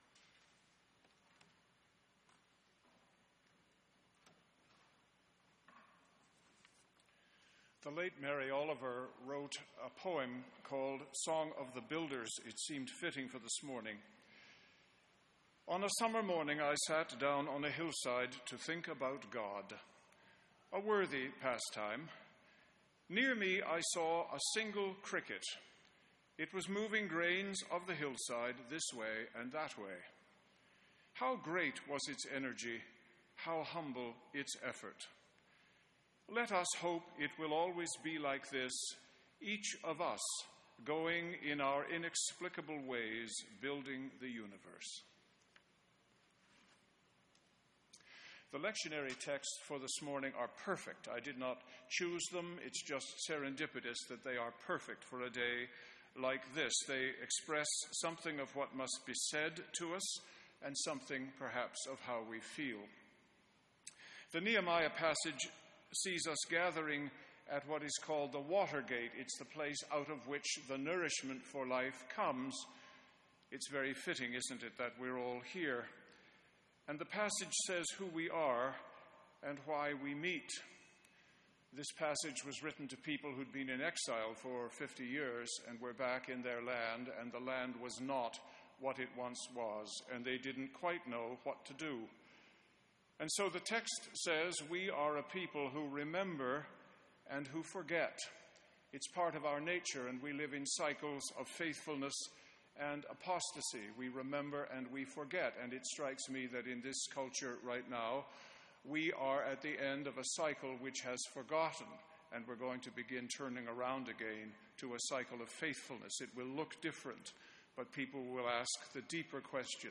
Sermons & Livestreams | Metropolitan United Church